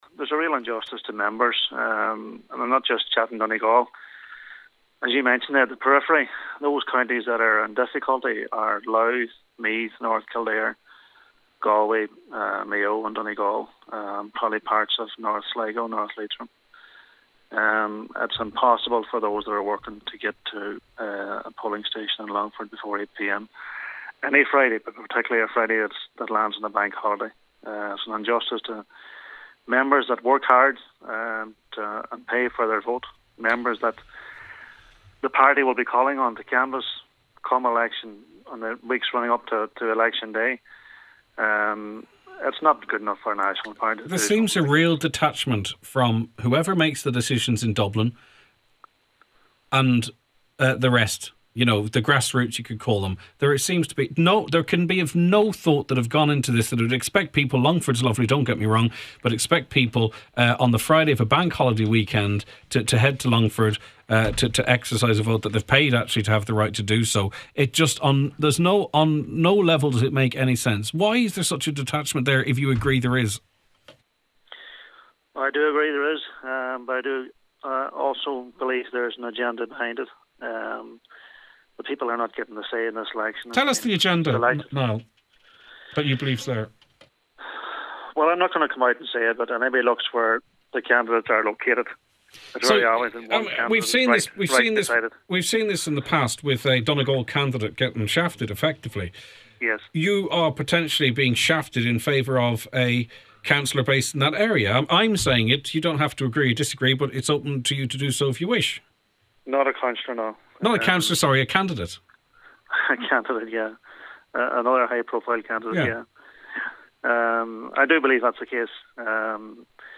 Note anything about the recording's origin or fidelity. Speaking on today’s Nine till Noon Show, he says it’s not too late for the party to open a second box closer to the North West region: